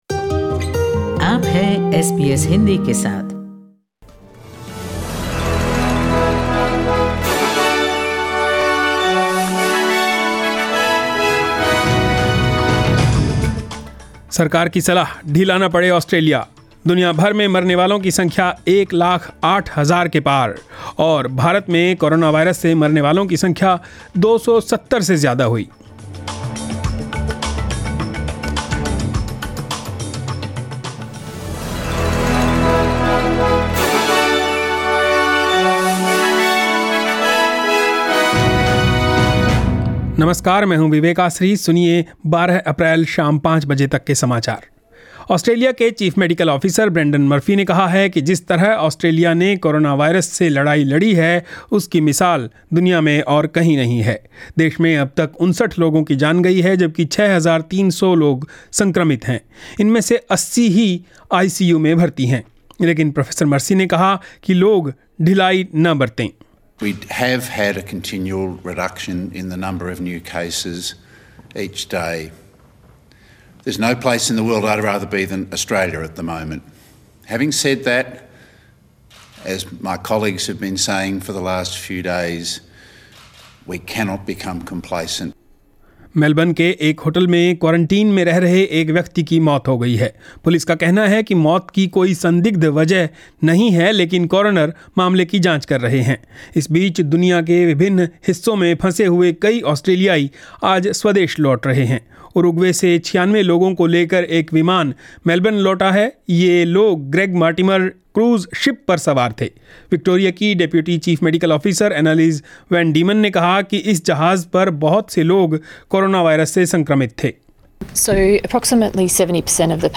Catch the latest news. In this bulletin: **Victoria's state of emergency extended as man dies while in Melbourne hotel quarantine.